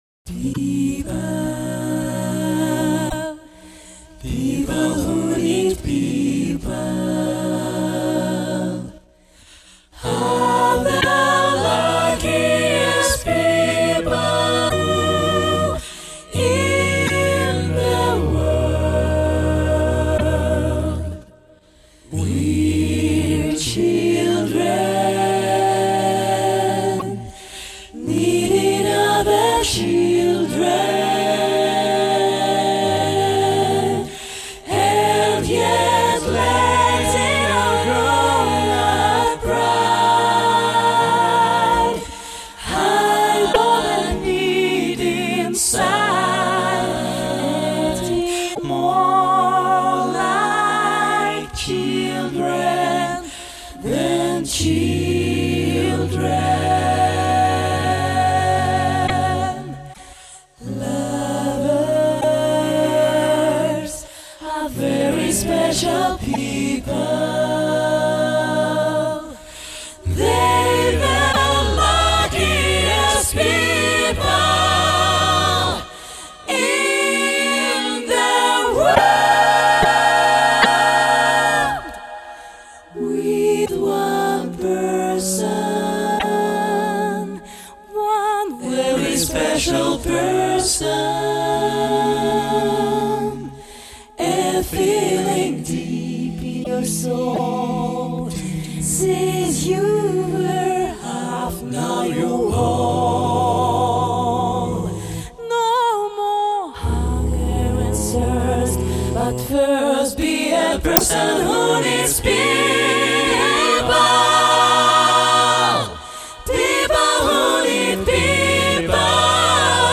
Жанр: джаз, акапелла